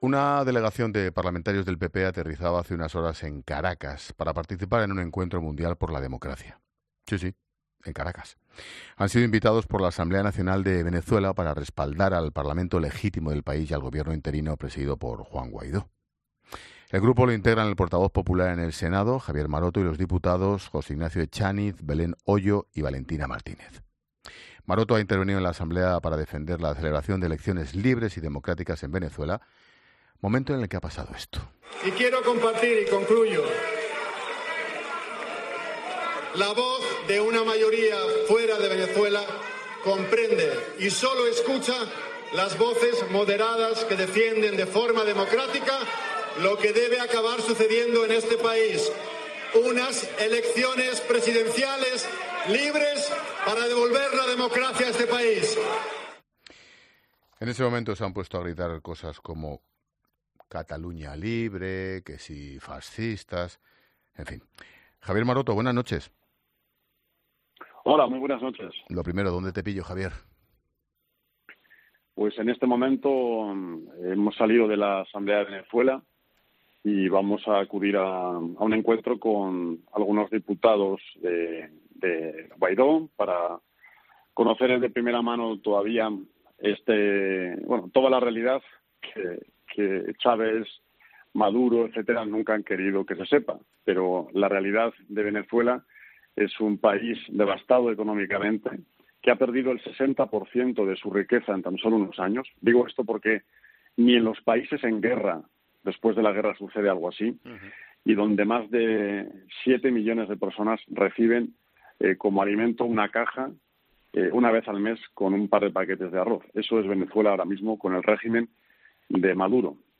Maroto ha respondido a la llamada de 'La Linterna' de COPE y ha explicado para sus micrófonos cómo ha vivido lo ocurrido.